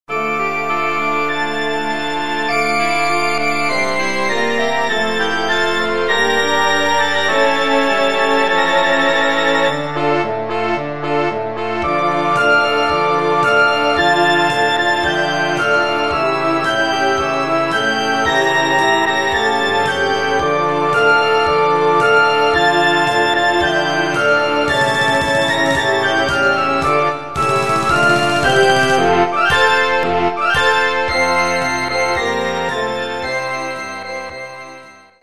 Orkiestrowa
ludowe